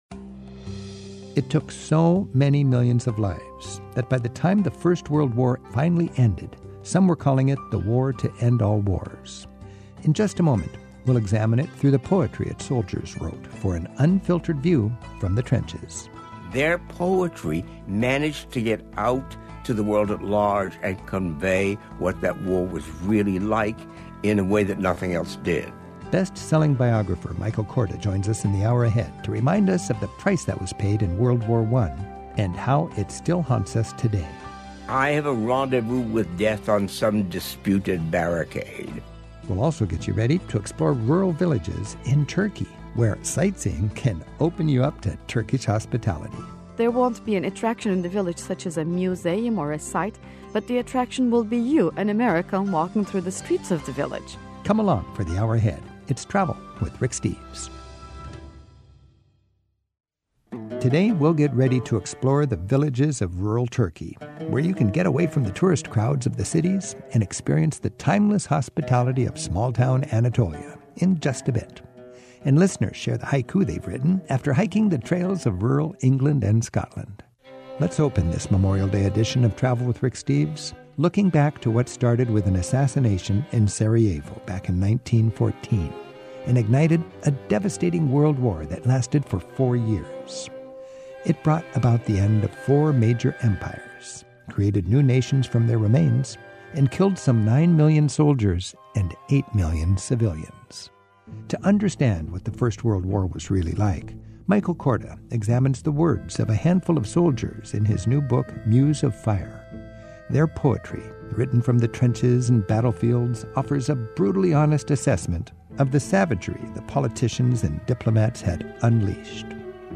My Sentiment & Notes 755 Soldier Poets of WWI; Village Turkey Podcast: Travel with Rick Steves Published On: Sat May 25 2024 Description: Historian, novelist, and legendary editor Michael Korda invites us to look back on World War I through the eyes of its soldier poets, whose works — often composed in the trenches — offer an unusually personal and uncensored perspective on the horrors of "the war to end all wars." And a Turkish tour guide takes listener calls while offering advice for finding a friendly welcome in the rural villages of Turkey, where the main attraction might just be…you.